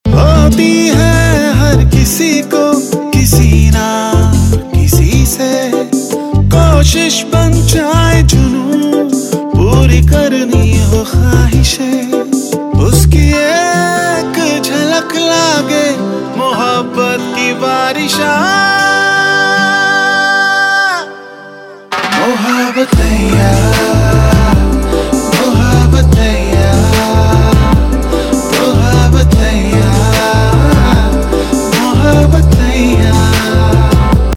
Pop Ringtones